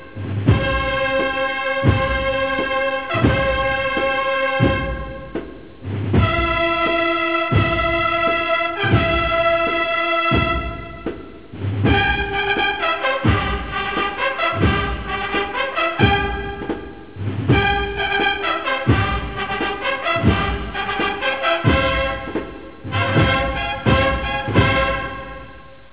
A Semana Santa Song.
saeta.wav